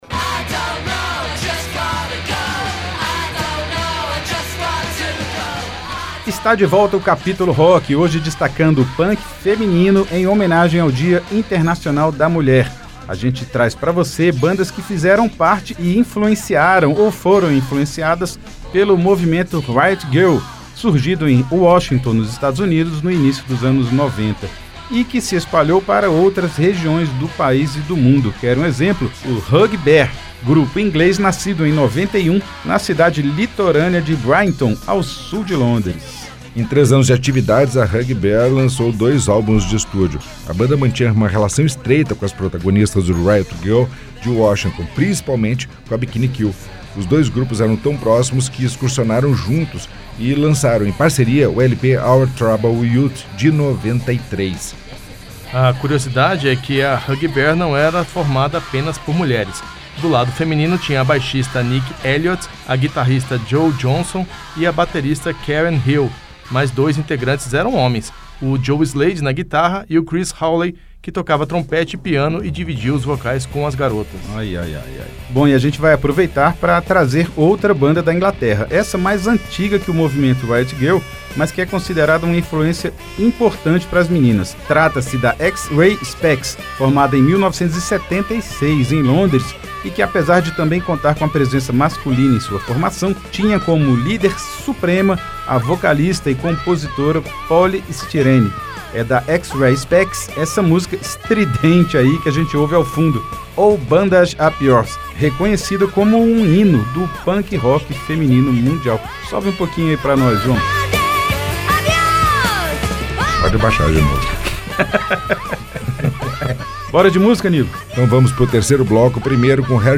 Punk Rock Feminino